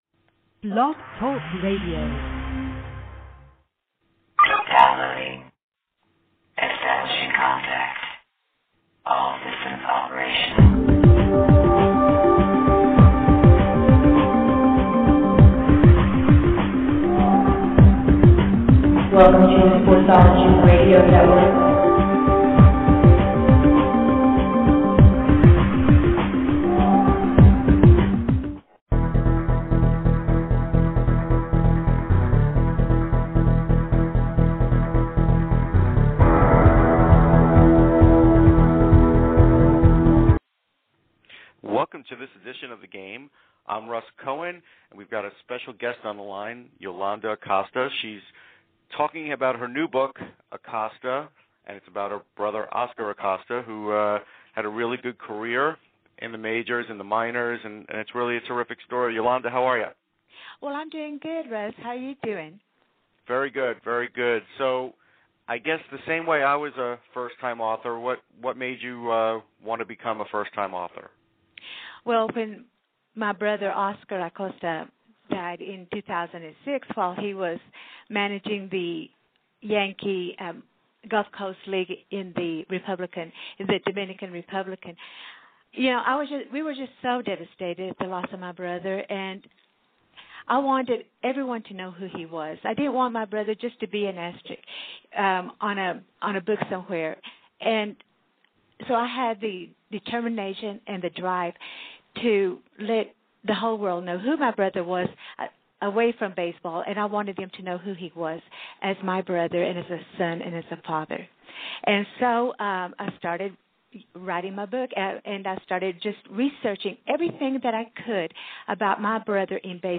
THE GAME - - BASEBALL PODCAST - - Special Interview